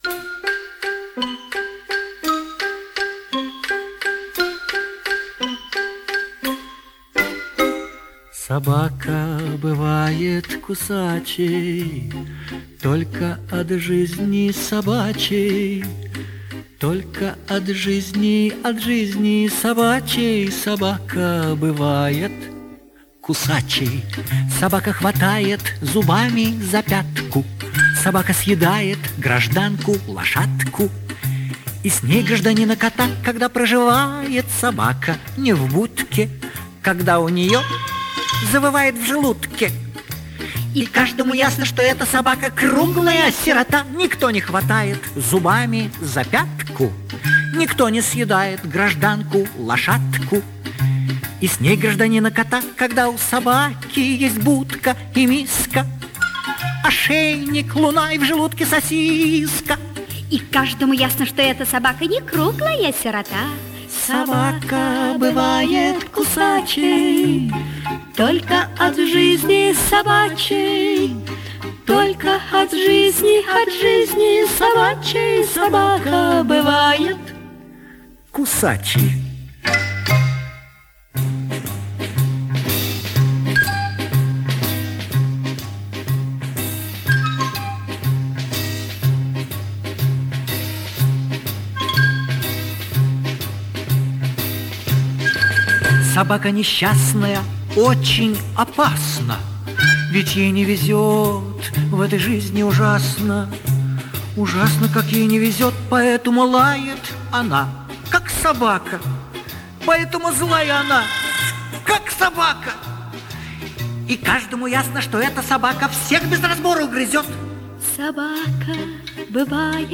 это добрая детская песенка из интересного мультфильма